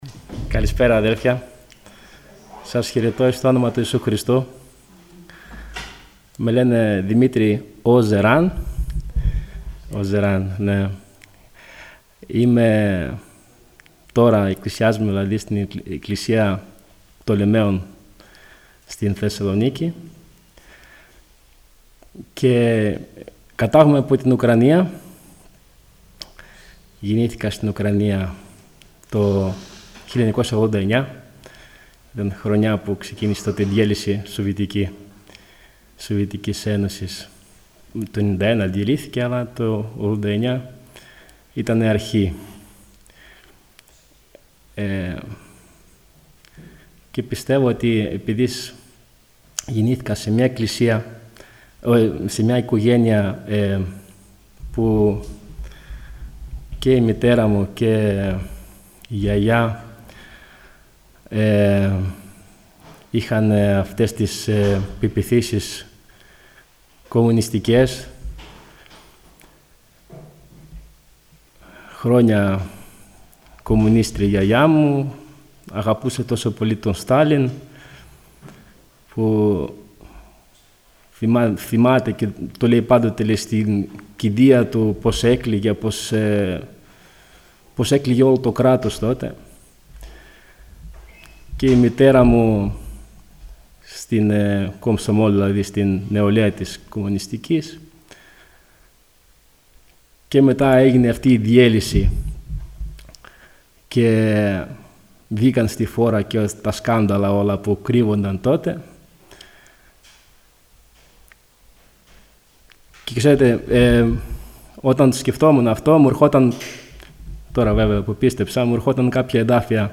Ομολογίες